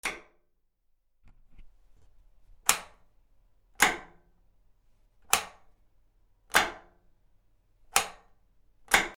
/ K｜フォーリー(開閉) / K35 ｜鍵(カギ)
鍵 浴室の鍵
『チャ』